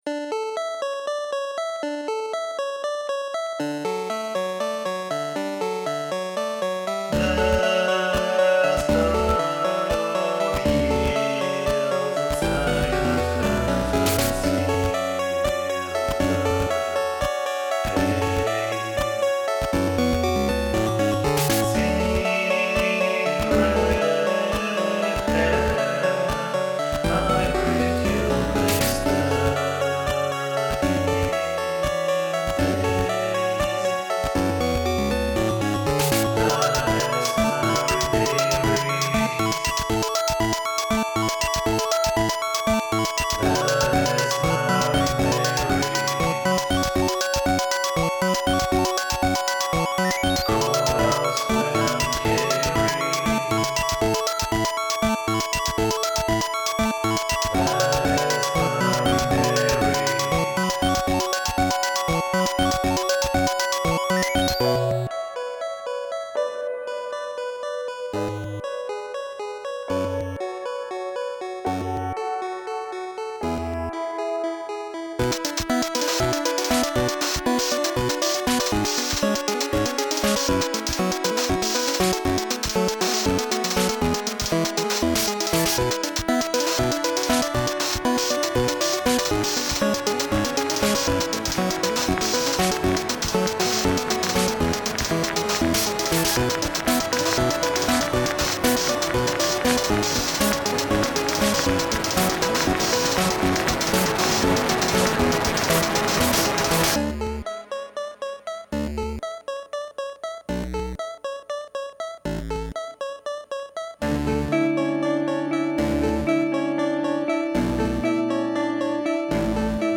Besoin d'avis sur un morceau plutôt pop
Plus particulièrement sur la voix.
Déjà parce que plus les notes descendent bas, moins je suis juste, mais aussi parce que la prise de son et l'EQ ne me plaisent pas trop.